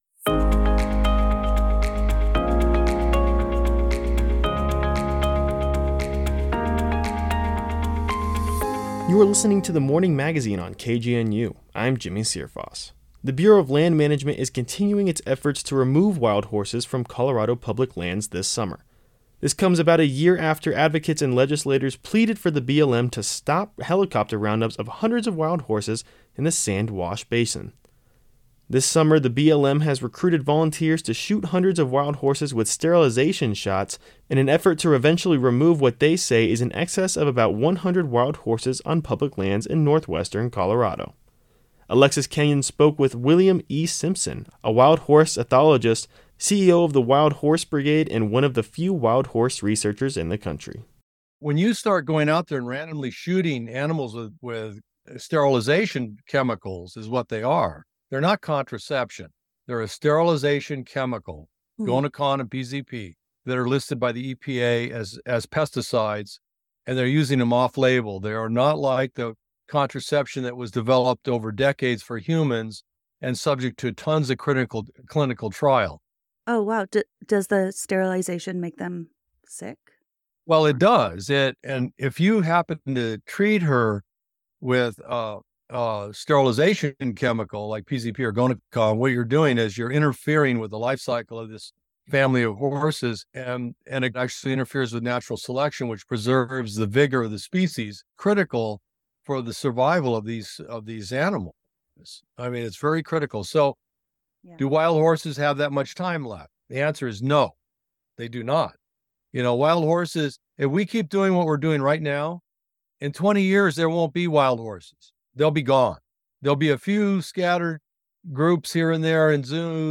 Interview Transcription: